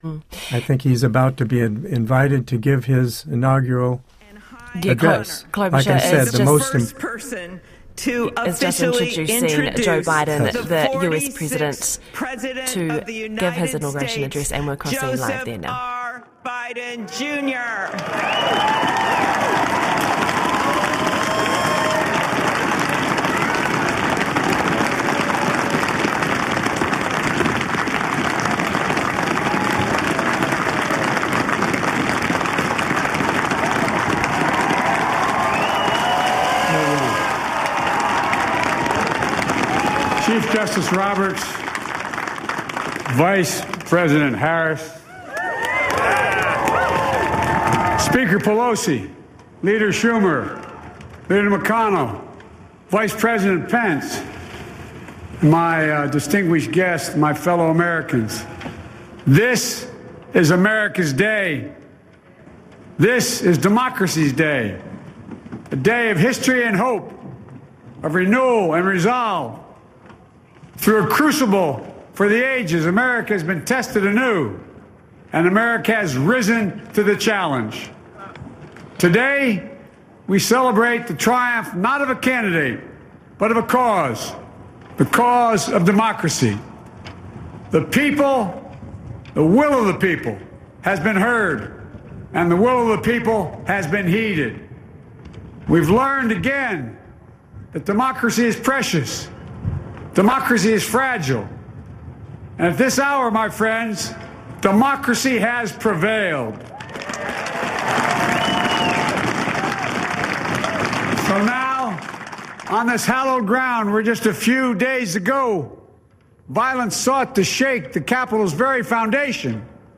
joe_biden_inauguration_speech_2020.mp3